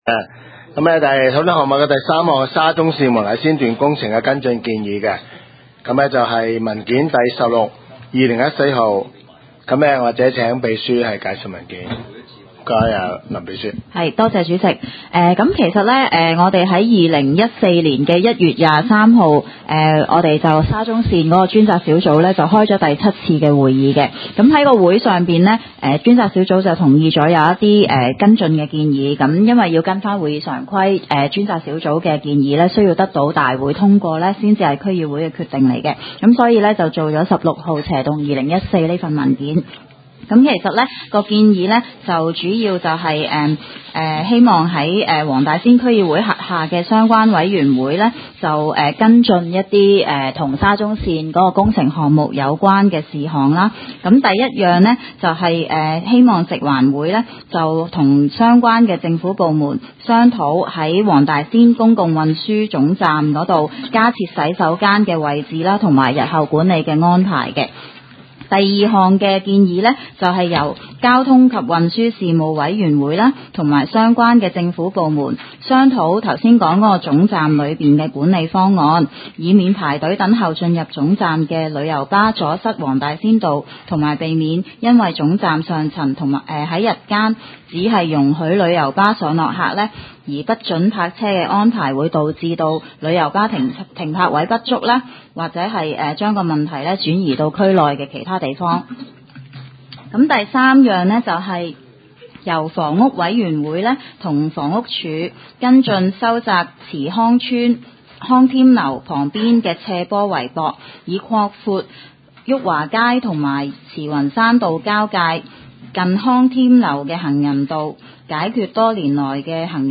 区议会大会的录音记录
黄大仙区议会会议室